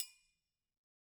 Triangle6-HitFM_v2_rr1_Sum.wav